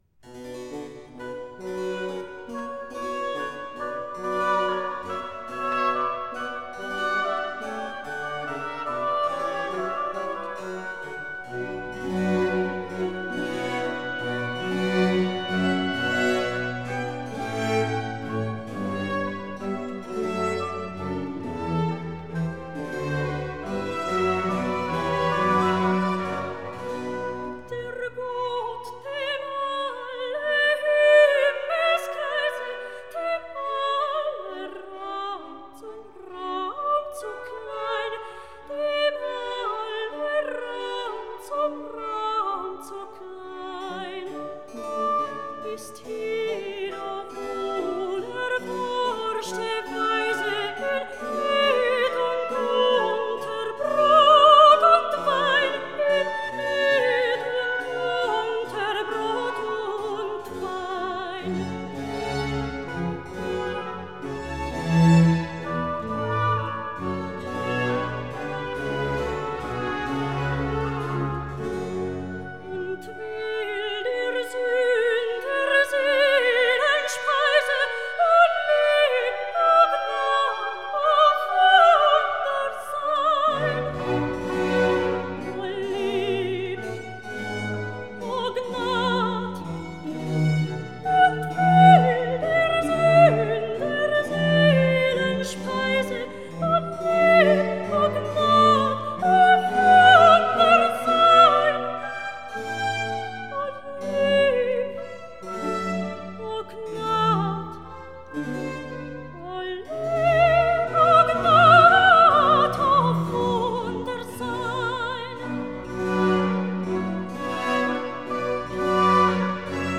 Aria